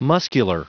Prononciation du mot muscular en anglais (fichier audio)
Prononciation du mot : muscular